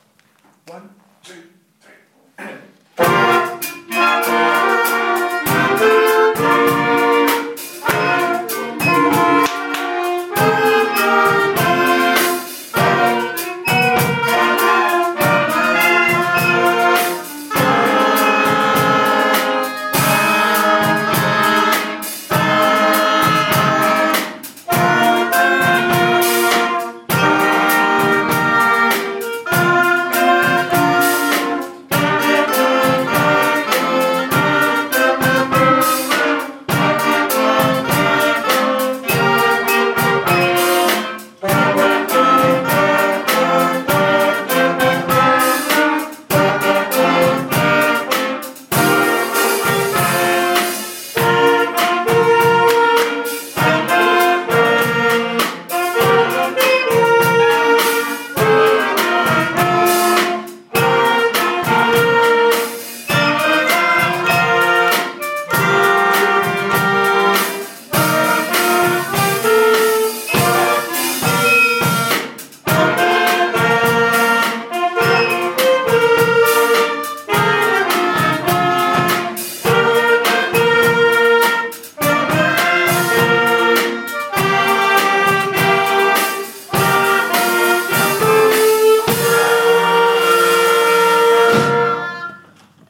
Primary Wind Band